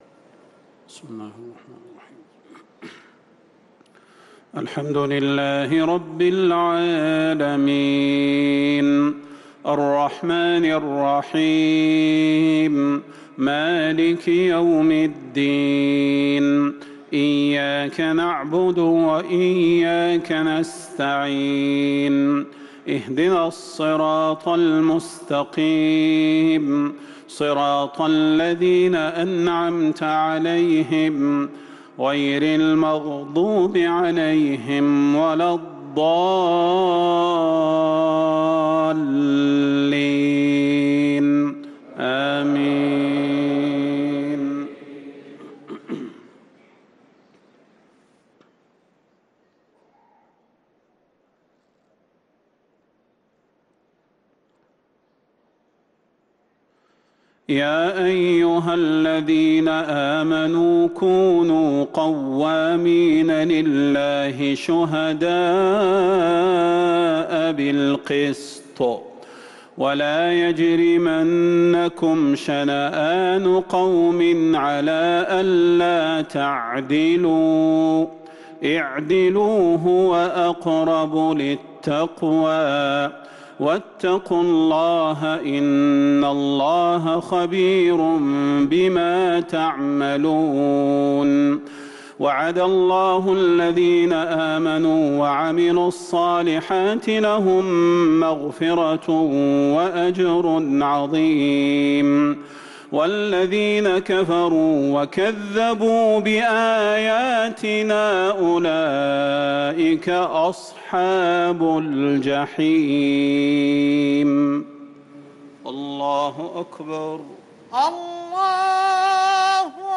صلاة المغرب للقارئ صلاح البدير 24 ربيع الأول 1445 هـ
تِلَاوَات الْحَرَمَيْن .